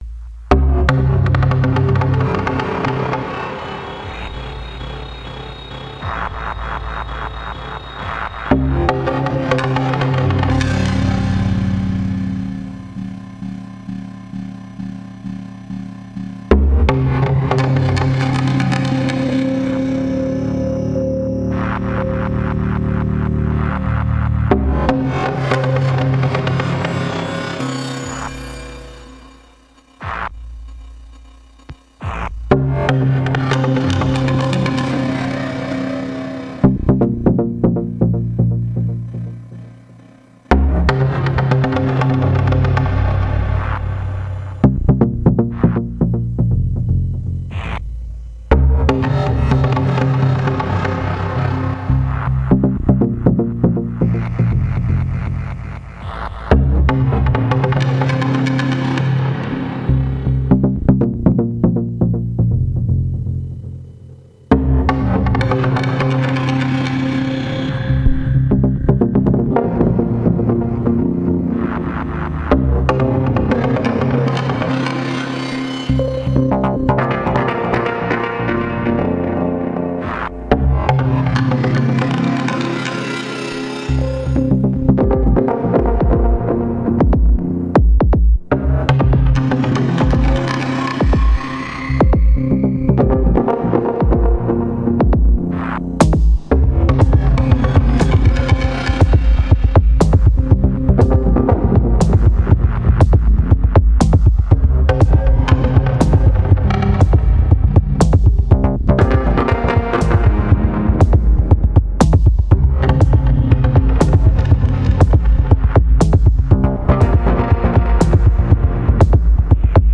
I plugged in my secondary OT, using one of them as a dedicated fx processor for external synths and one as a drum machine/drum sequencer.
Some light acid jamming:
OT x2, Nord Wave, Nord Drum3P, Anyma Phi, Brain Tec TB-3.